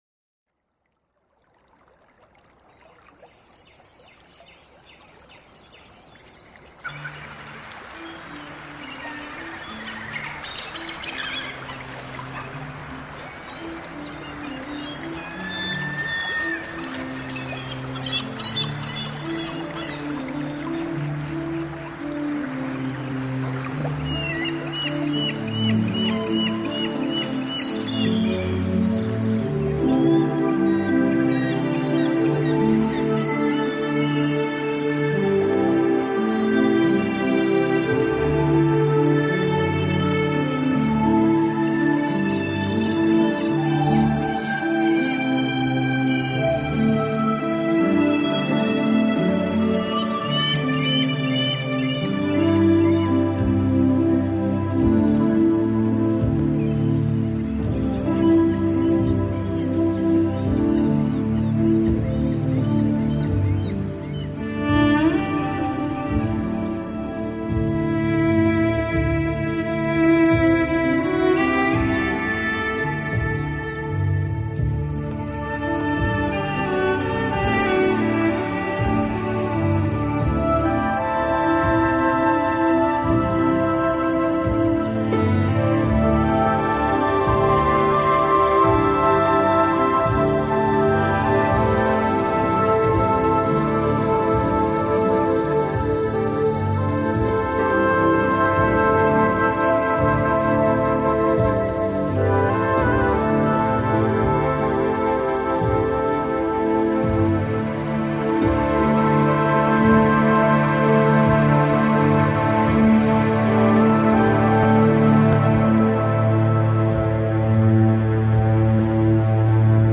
自然聲響與音樂的完美對話
新世紀的音樂形式使躁動的靈魂得到最溫柔的撫慰，你將在夜裡
海浪、流水、鳥鳴，風吹過樹葉，雨打在屋頂，
大自然的原始採樣加上改編的著名樂曲合成了天籟之音。